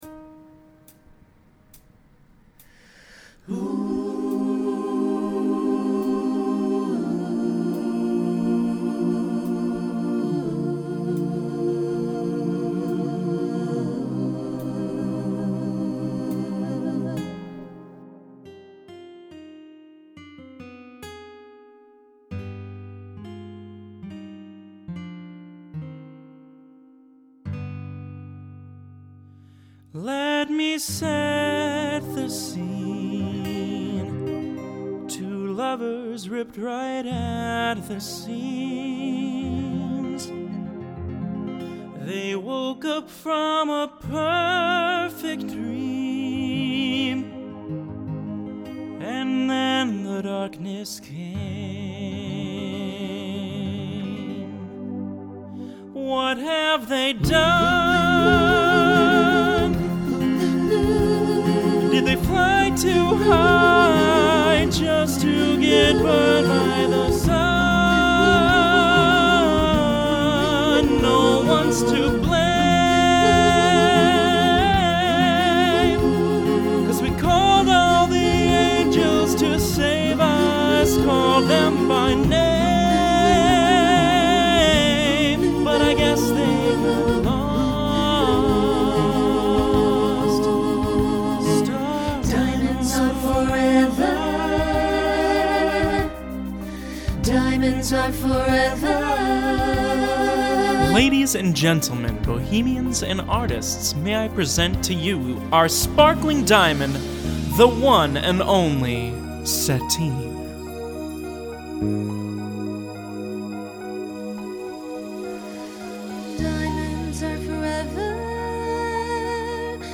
Genre Broadway/Film , Pop/Dance
Voicing SATB